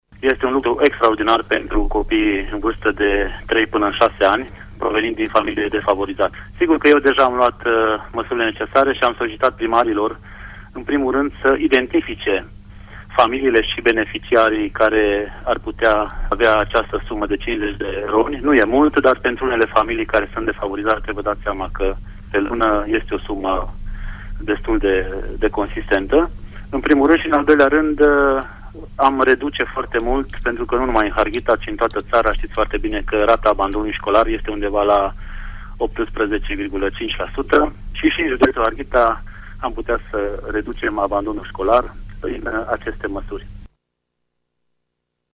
Invitat azi în Pulsul zilei, prefectul de Harghita a declarat: